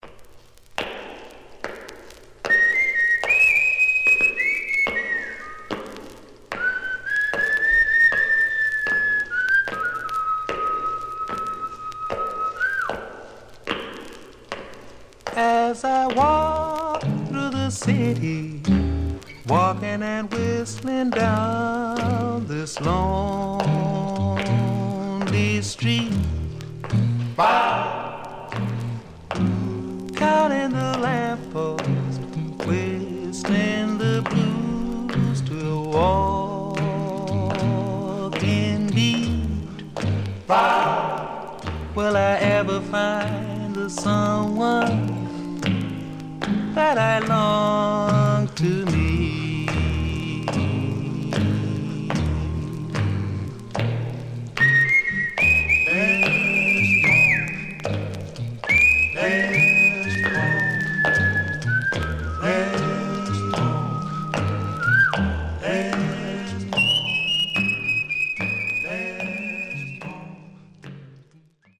Stereo/mono Mono
Male Black Groups